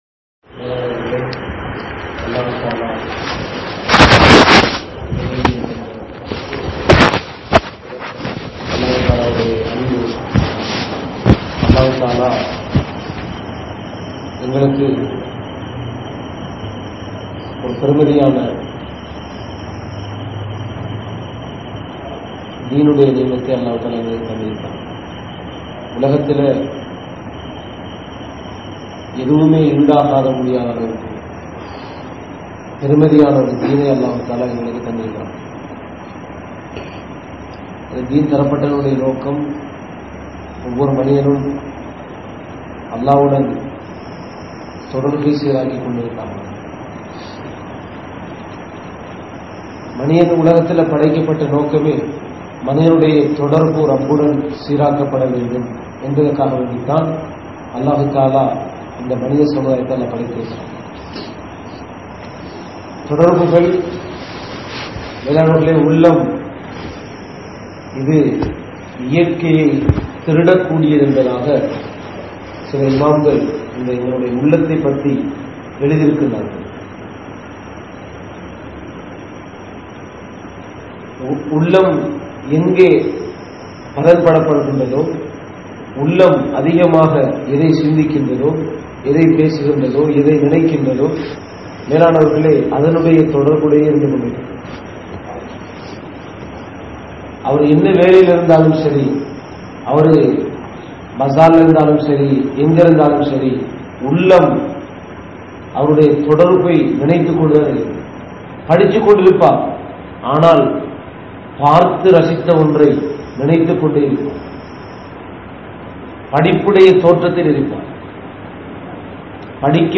Tholuhaiel Allah`vai Maranthavarhal (தொழுகையில் அல்லாஹ்வை மறந்தவர்கள்) | Audio Bayans | All Ceylon Muslim Youth Community | Addalaichenai
Akbar Masjidh